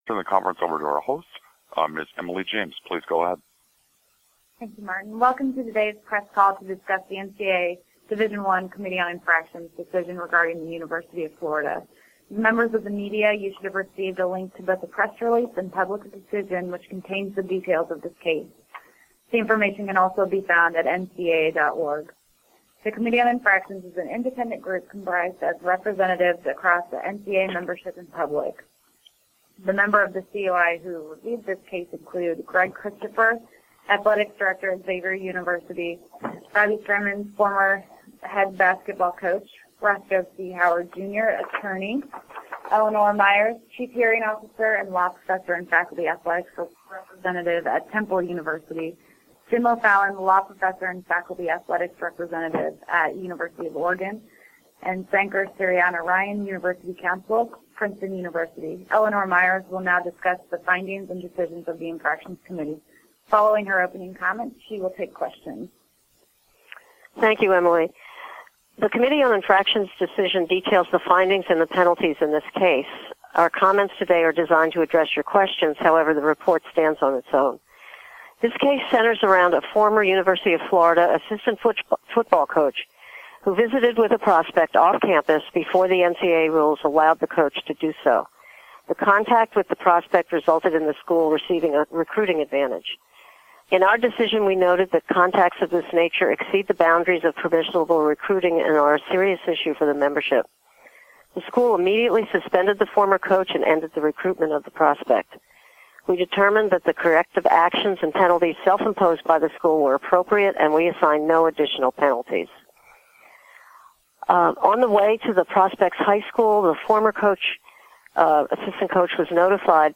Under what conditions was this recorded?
NCAA Division I Committee on Infractions Teleconference regarding the University of Florida